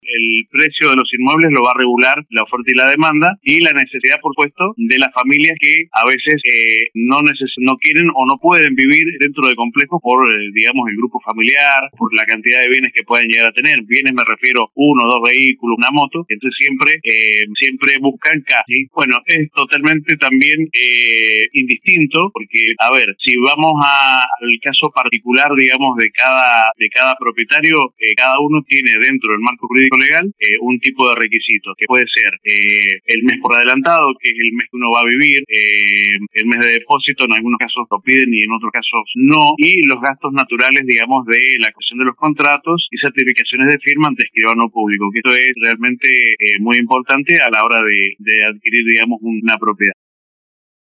En una entrevista con LV18